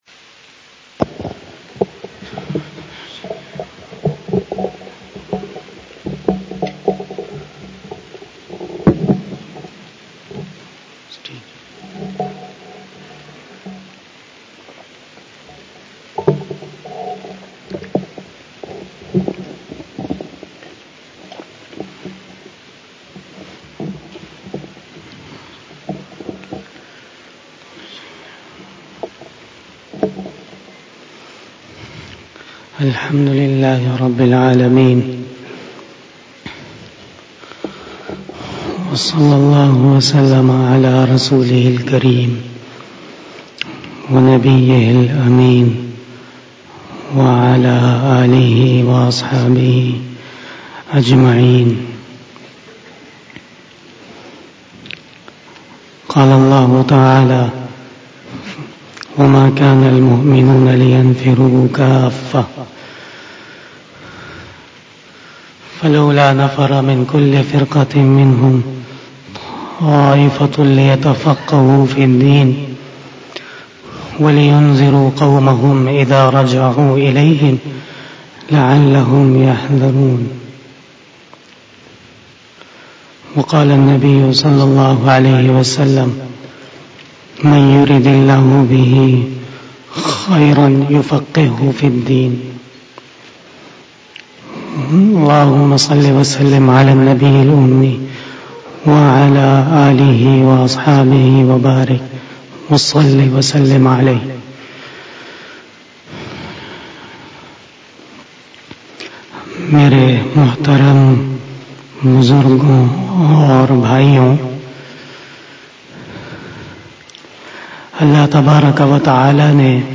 بیان شب جمعۃ المبارک 08 جمادی الثانی 1442ھ